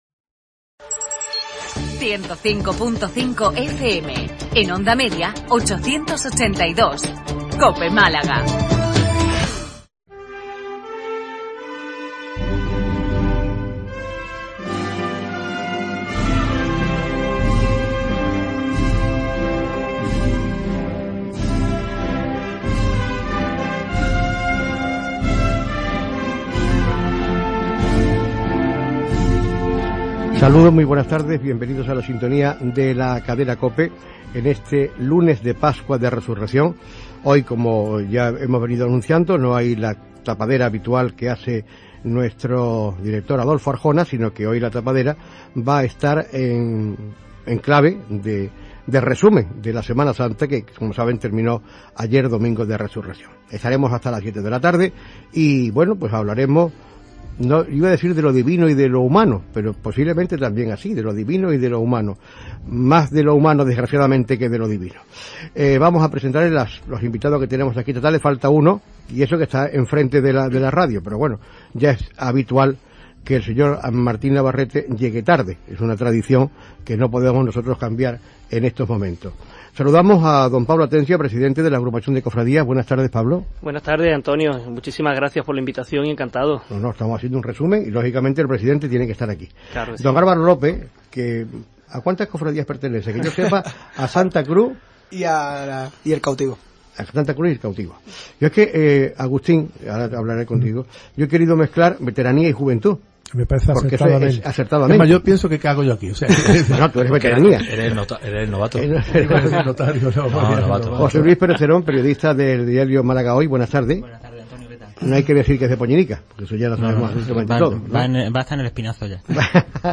Primera parte de la tertulia cofrade